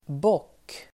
Uttal: [båk:]